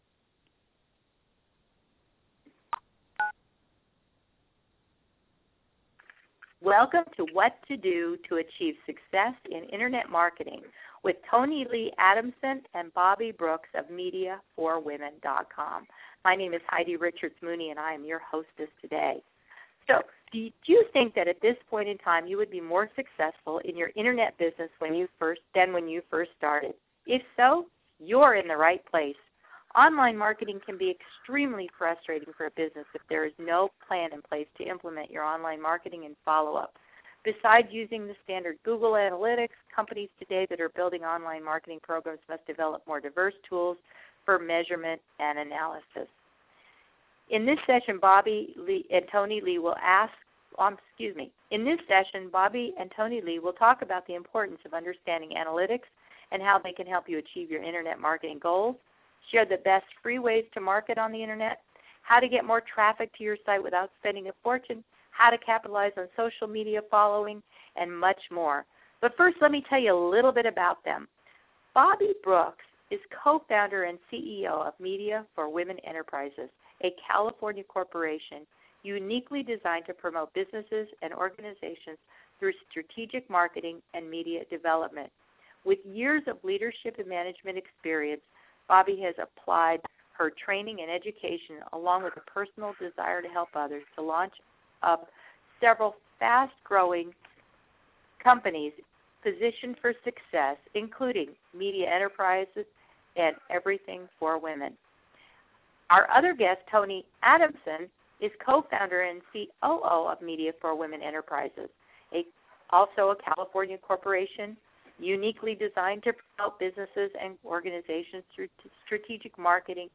Listen to the Internet Marketing Teleconference Playback
Internet marketing free teleclass
Media4WomenTeleconference.mp3